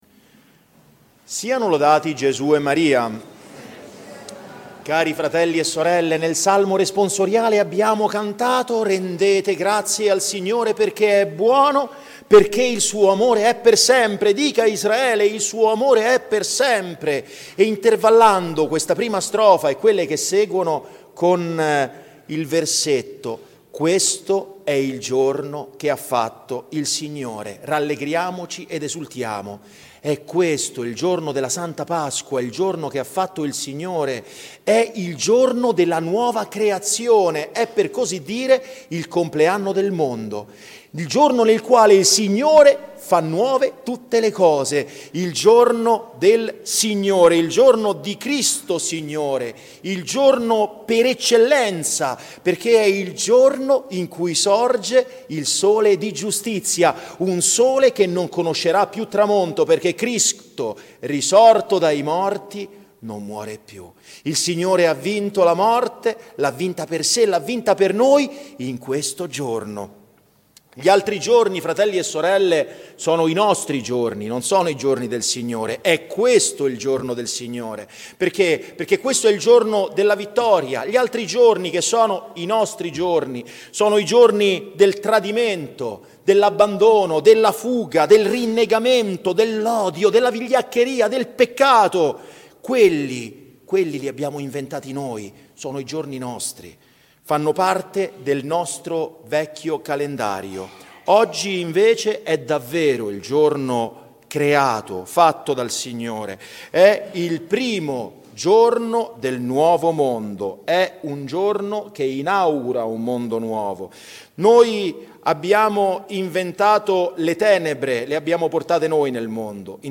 Download - Omelia 27 Agosto 2024, SANTA MONICA | Podbean